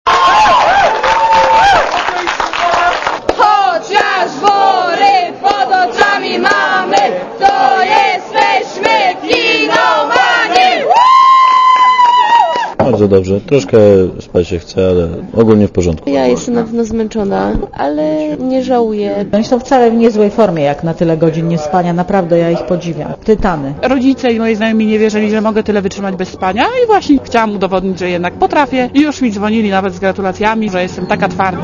Posłuchaj, jak cieszyli się rekordziści